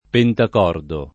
pentacordo [ pentak 0 rdo ]